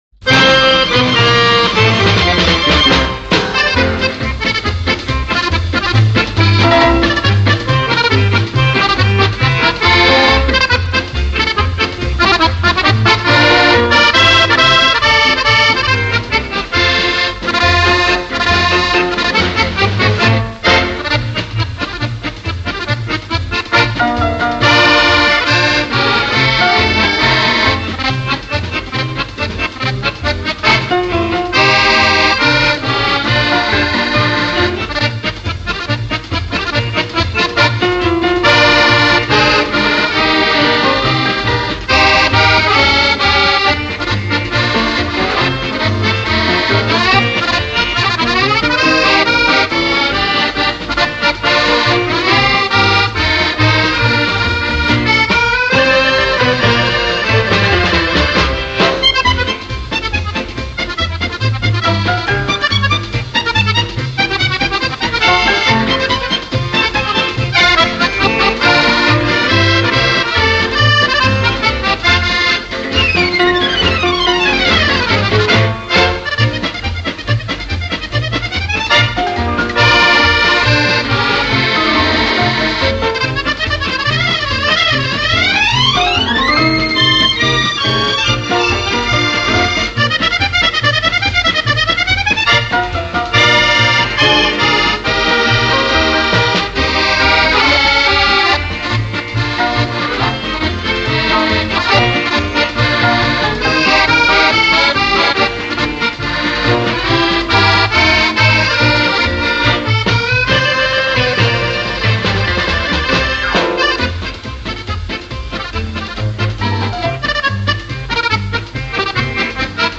音乐类型: Pop, Instrumental Accordeon